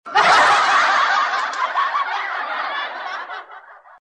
ketawaxox Meme Sound Effect
Category: Reactions Soundboard